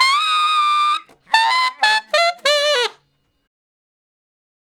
066 Ten Sax Straight (D) 32.wav